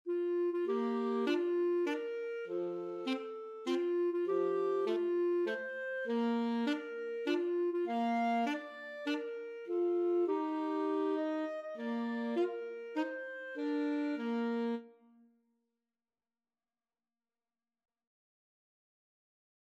ClarinetAlto Saxophone
3/4 (View more 3/4 Music)